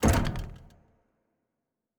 Special Click 18.wav